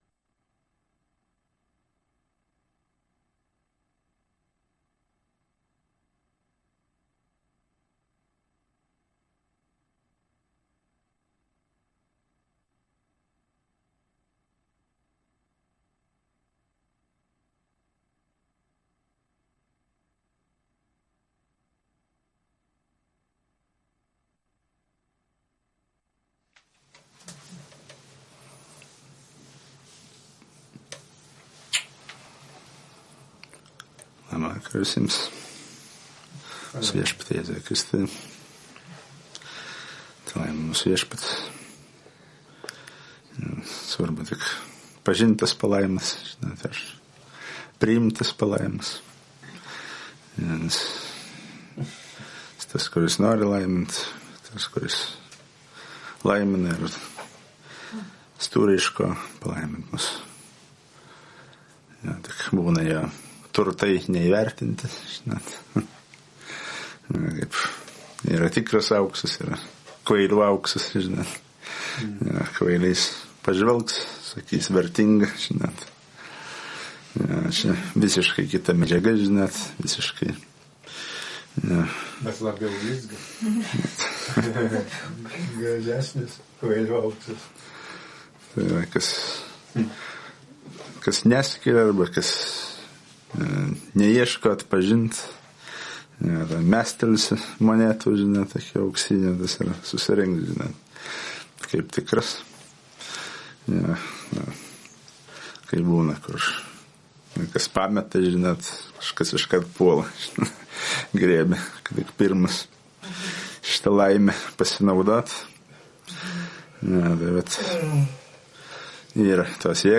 Pamokslai
Surinkimo "Paėmimo Ugnis" pamokslų archyvas